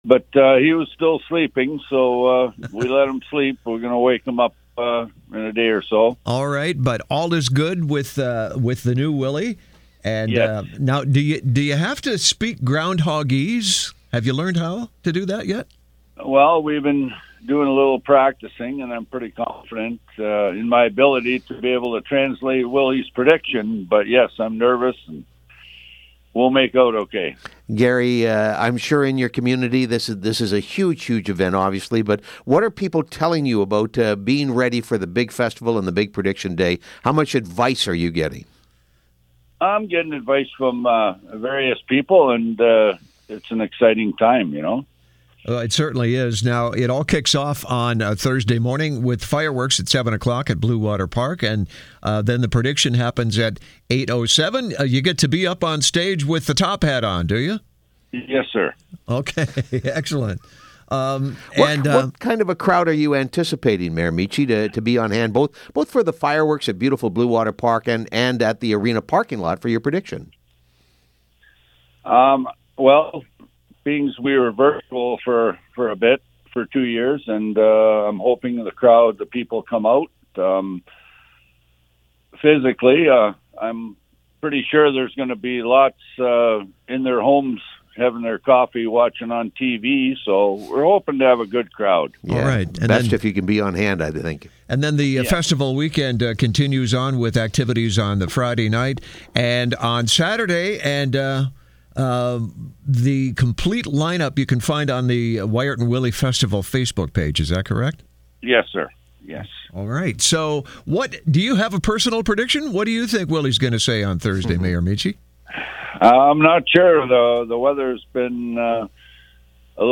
Ground Hog Day Approaches. South Bruce Peninsula Mayor Gary Michi on CFOS